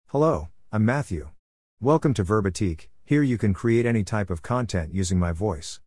MaleUS English
MatthewMale US English AI voice
Matthew is a male AI voice for US English.
Voice sample
Matthew delivers clear pronunciation with authentic US English intonation, making your content sound professionally produced.